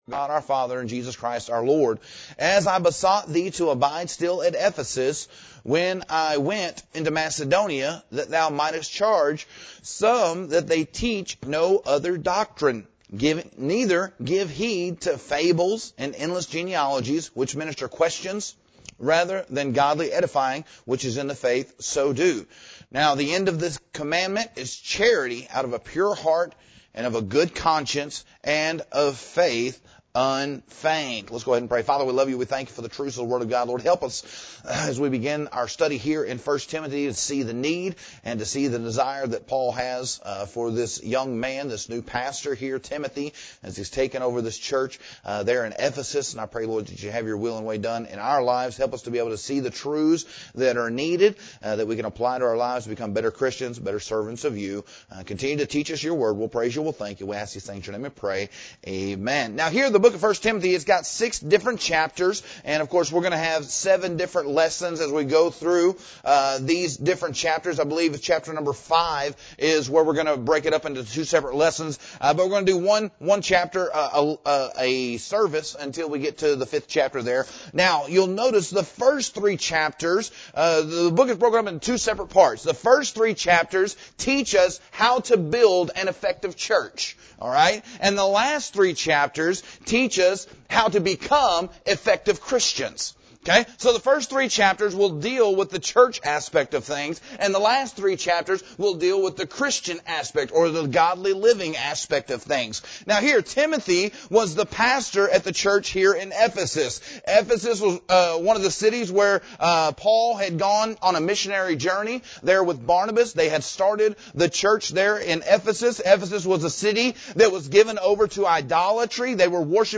This sermon is built on 1 Timothy chapter 1.